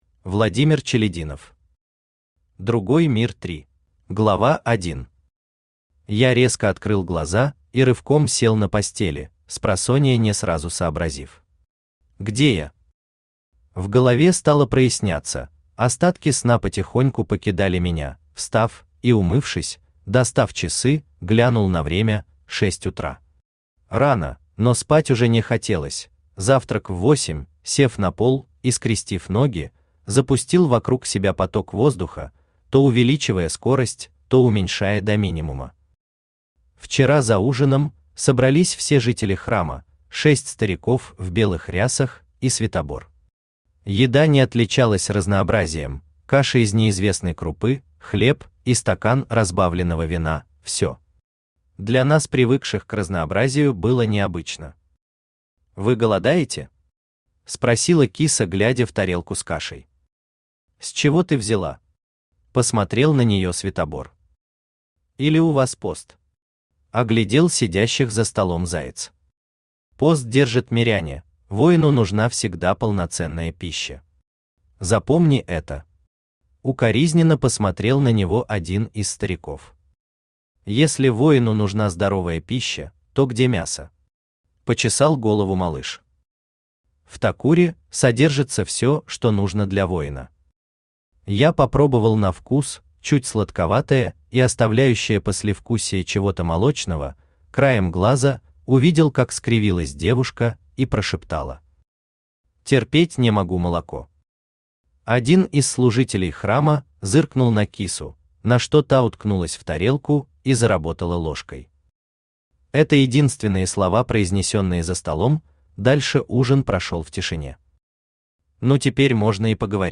Aудиокнига Другой мир 3 Автор Владимир Иванович Челядинов Читает аудиокнигу Авточтец ЛитРес. Прослушать и бесплатно скачать фрагмент аудиокниги